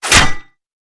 Projectile Metal Impact Sound.wav